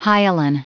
Prononciation du mot hyaline en anglais (fichier audio)
Prononciation du mot : hyaline